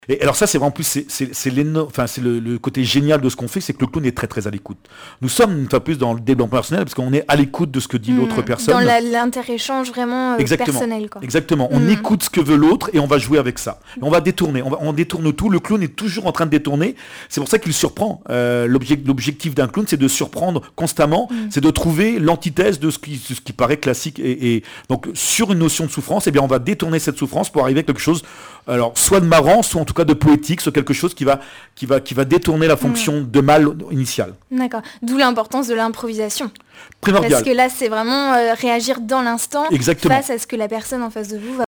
émission de RCF Vendée sur les activités de Libr'arthé
Catégorie Témoignage